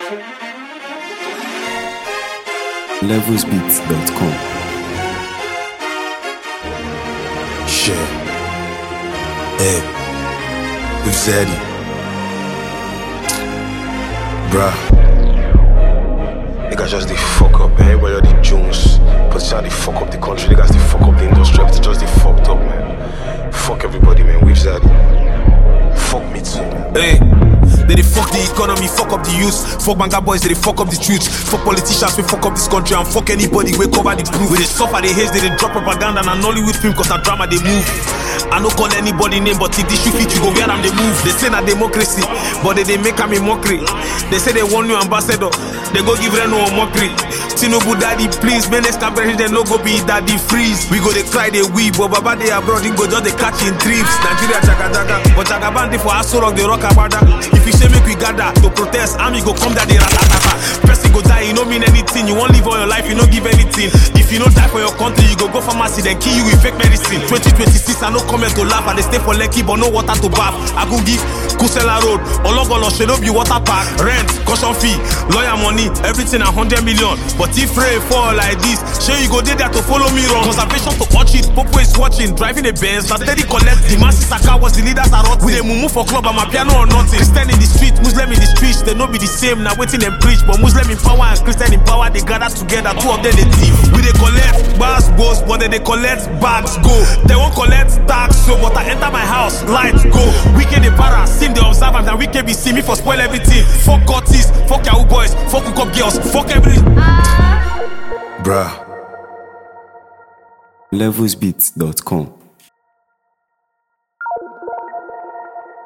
Highly skilled Nigerian rapper and lyricist
hard-hitting and thought-provoking freestyle
If you’re a fan of conscious hip-hop